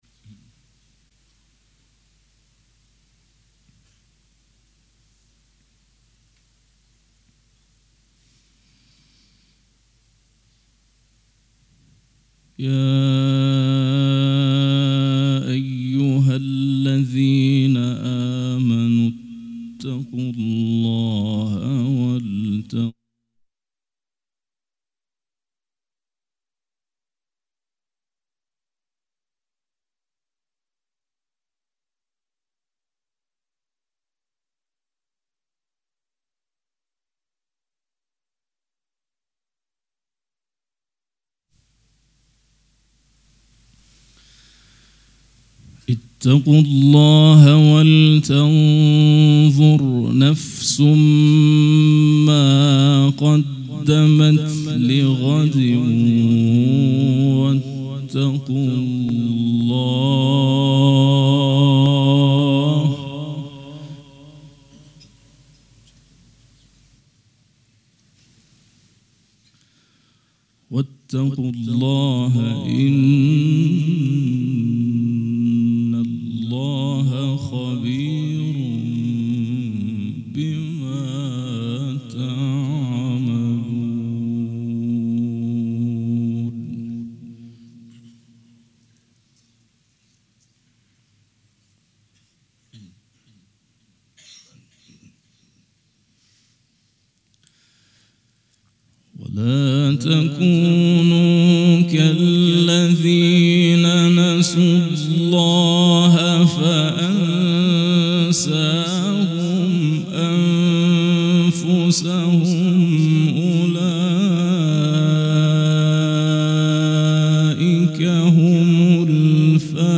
قرائت قرآن کریم
شب پنجم محرم الحرام‌ پنجشنبه ۱5 مهرماه ۱۳۹۵ هيئت ريحانة الحسين(س)